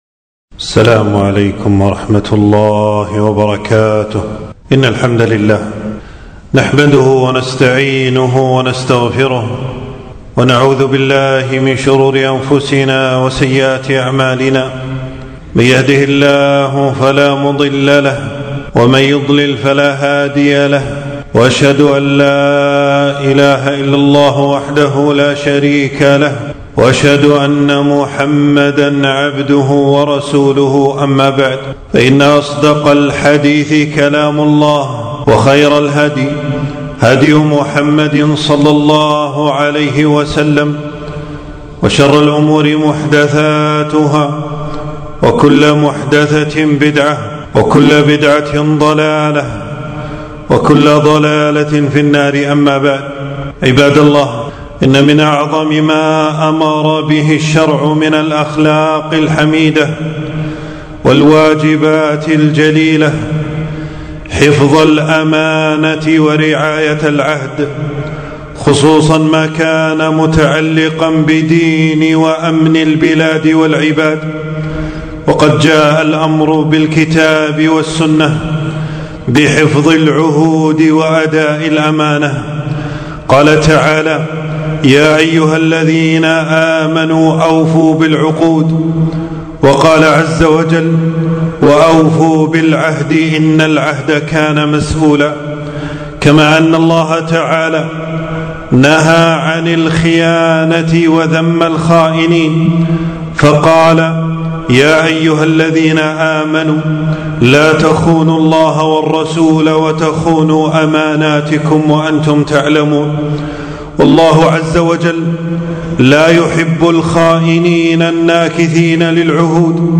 خطبة - خيانة الأوطان